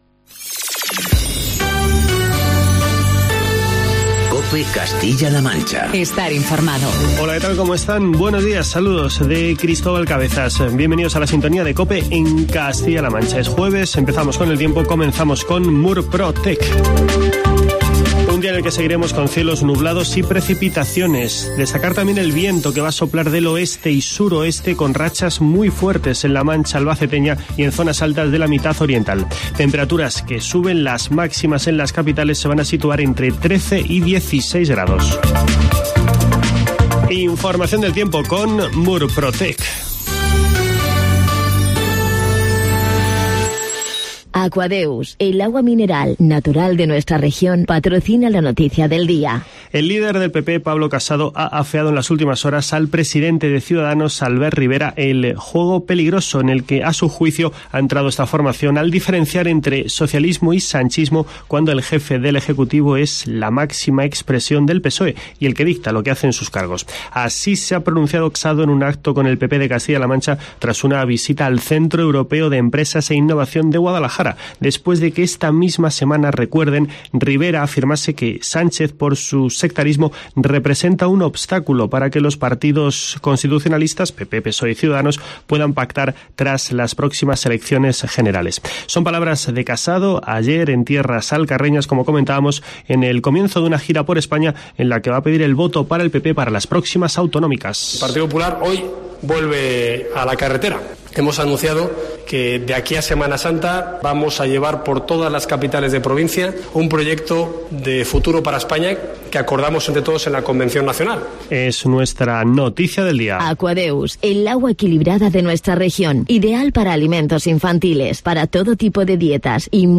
Informativo matinal de COPE Castilla-La Mancha.